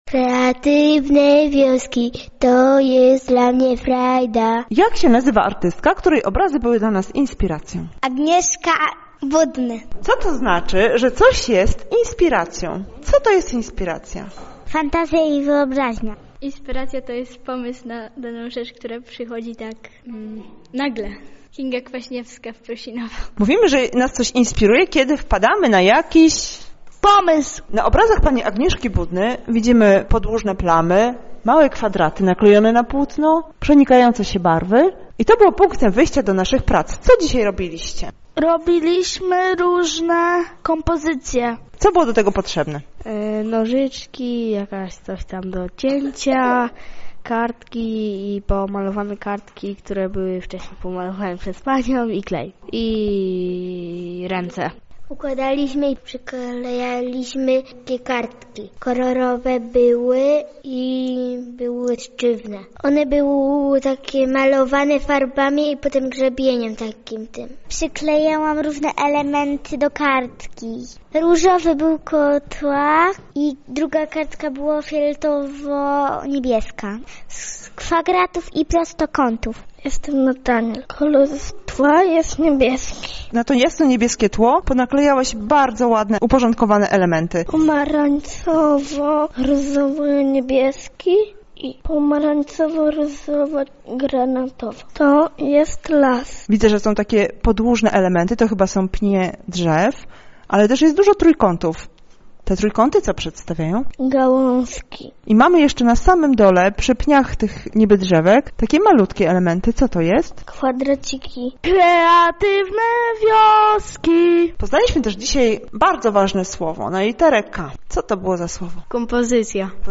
KW_kompozycja_reportaY.mp3